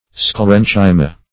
Sclerenchyma \Scle*ren"chy*ma\, n. [NL., from Gr. sklhro`s hard